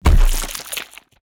body_hit_finisher_42.wav